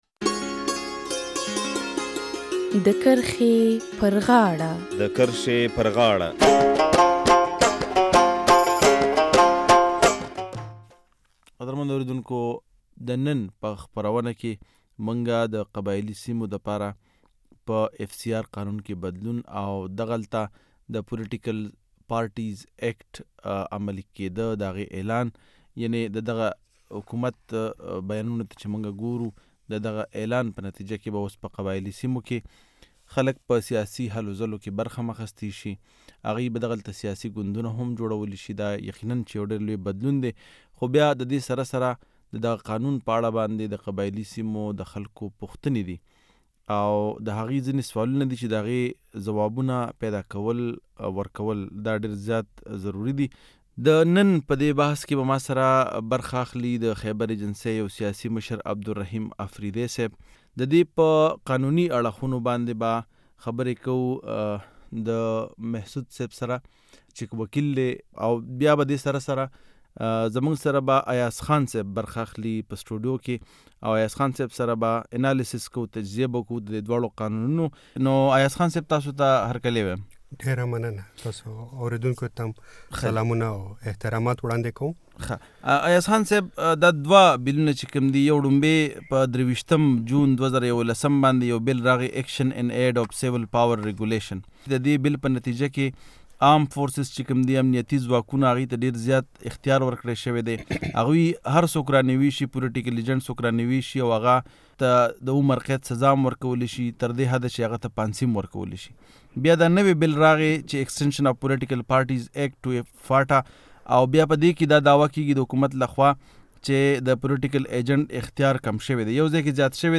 د پاکستان ولسمشر آصف علي زرداری د روانه میاشت د قبایلي سیمو په قانون فرنټیر کرایمز ریګولیشن کې د بدلون حکم جاری کړو چې په نتیجه کې یی سیاسی ګوندونو ته په قبایلي سیمو کې د سیاسی هلو ځلو اجازت تر لاسه شو. د نن په خپرونه کې ددغه قانون په بیلابیلو اړخونو بحث کوو.